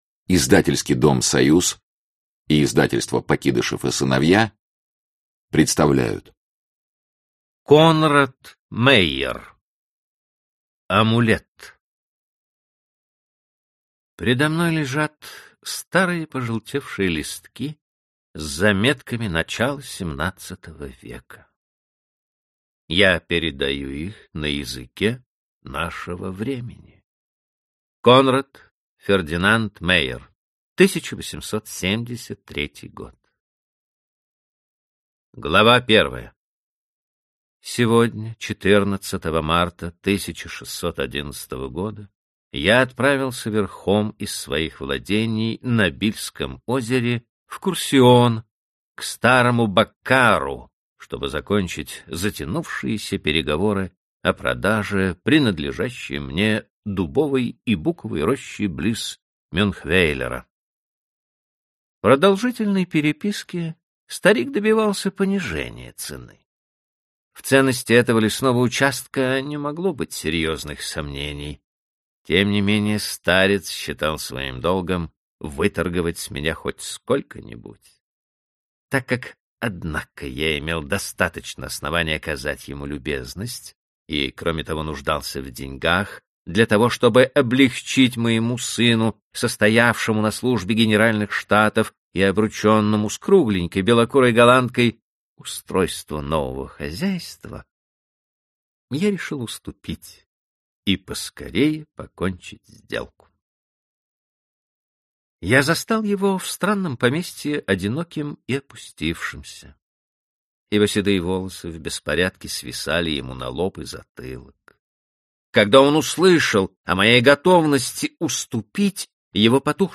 Аудиокнига Амулет | Библиотека аудиокниг